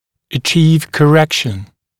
[ə’ʧiːv kə’rekʃn][э’чи:в кэ’рэкшн]добиваться коррекции